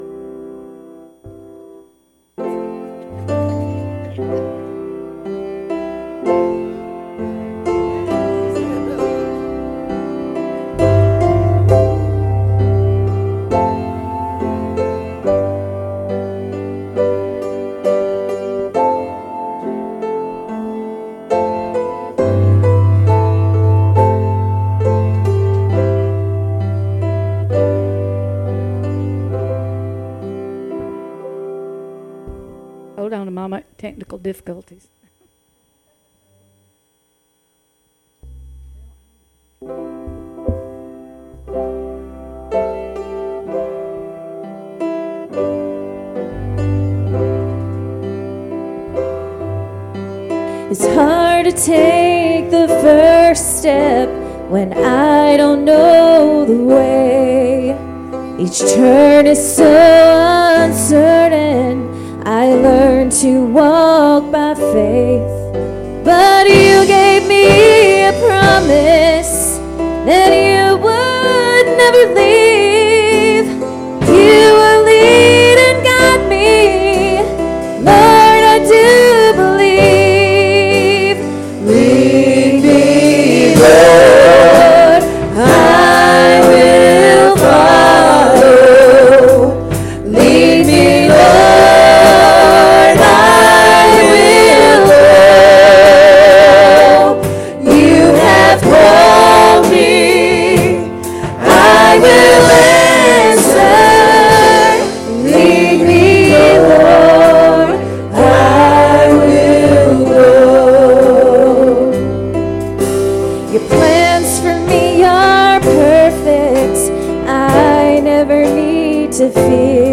Service Type: Sunday Evening Services